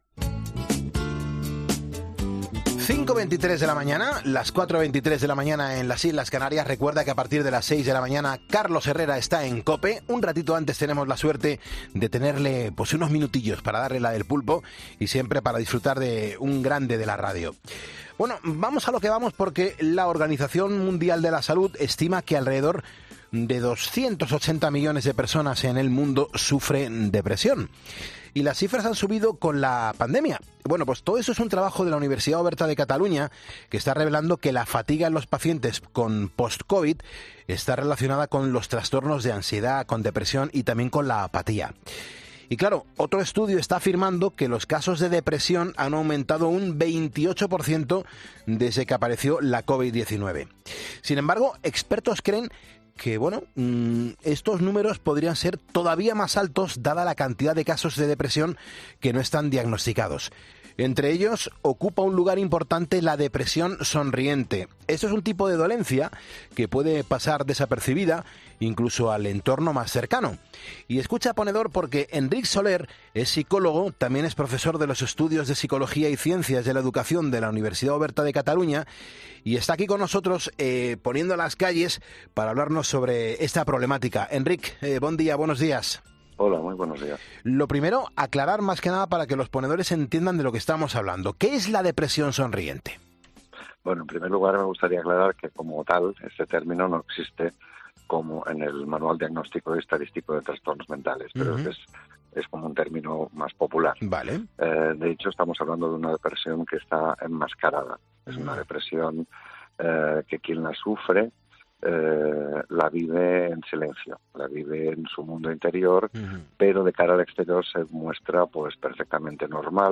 psicólogo experto en salud mental.